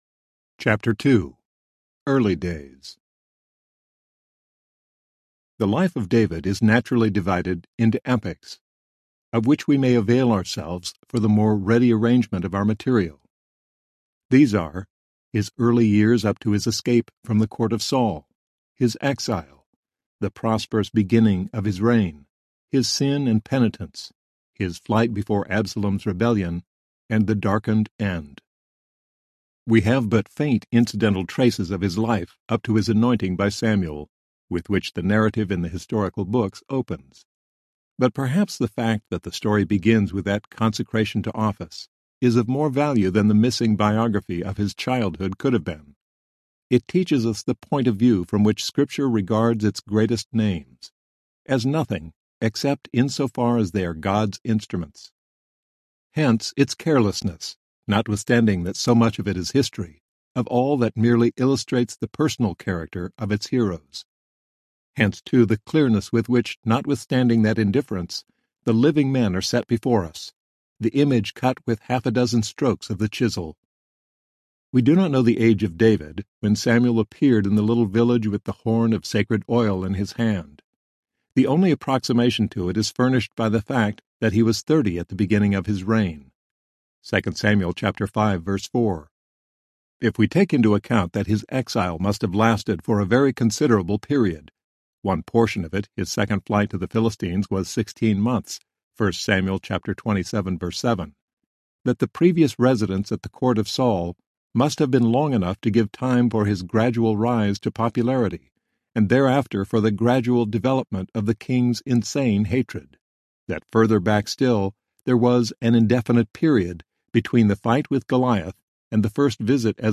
Narrator
5.5 Hrs. – Unabridged